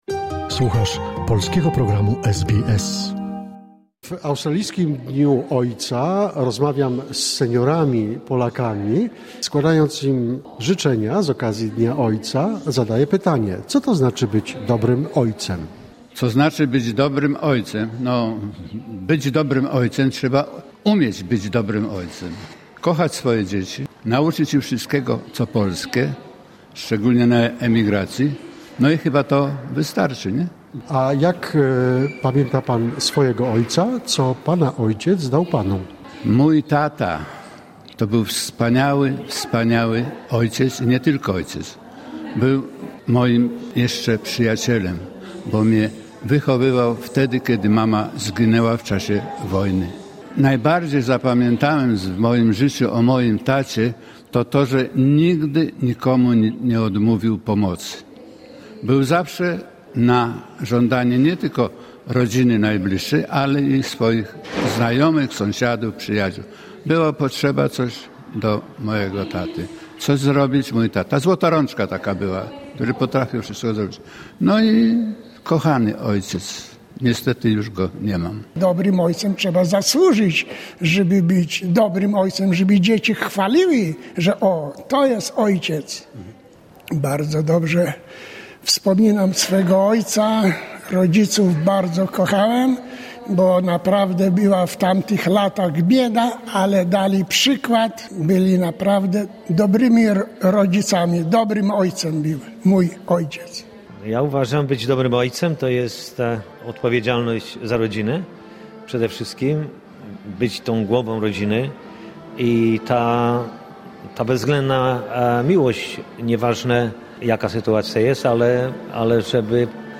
Dzień Ojca jest również okazją do refleksji nad rolą ojców, ich wpływem na wychowanie dzieci i wkładem w życie rodzinne. Zapytaliśmy polskich ojców seniorów w Melbourne, co oznacza bycie dobrym ojcem?